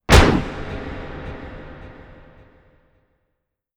Furious.wav